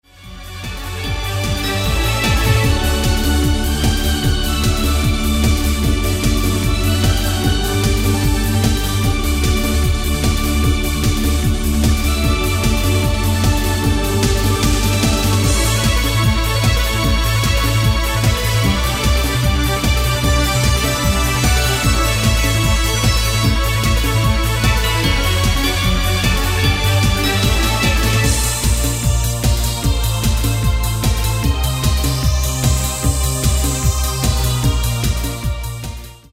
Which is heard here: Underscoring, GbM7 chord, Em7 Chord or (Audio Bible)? Underscoring